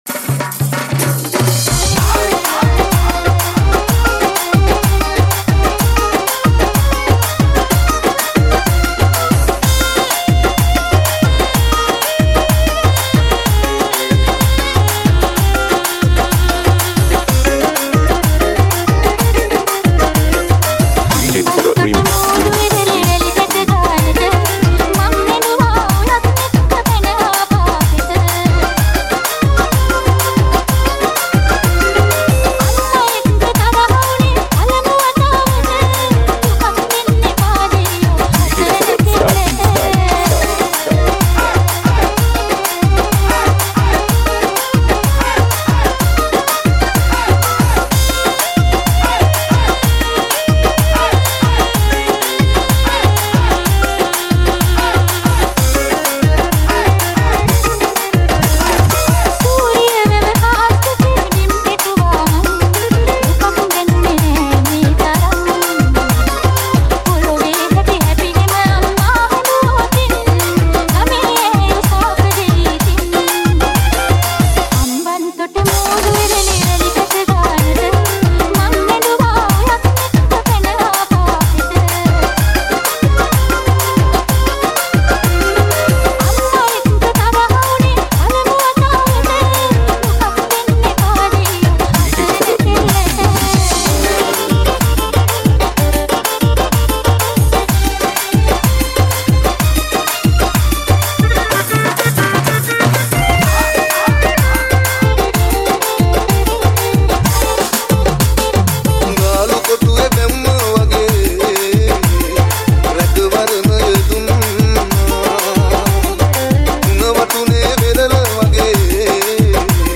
Genre - Classical